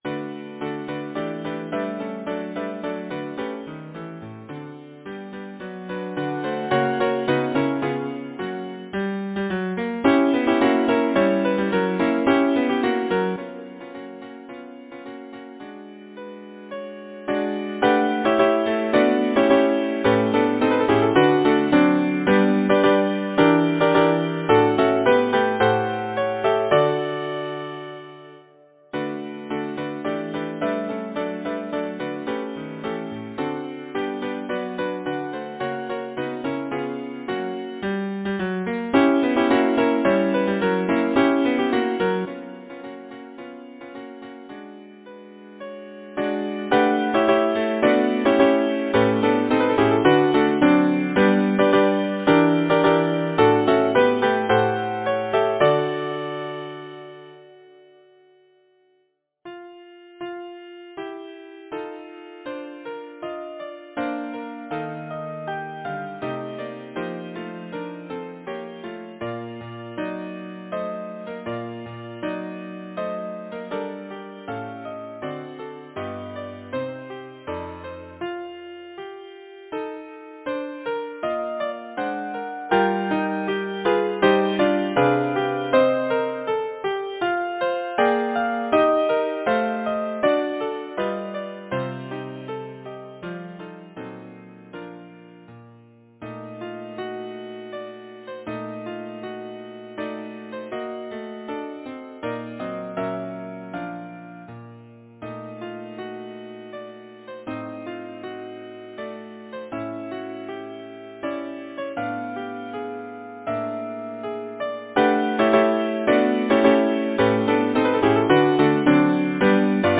Number of voices: 5vv Voicing: SSATB Genre: Secular, Partsong
Language: English Instruments: A cappella